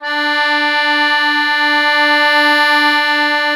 MUSETTE1.4SW.wav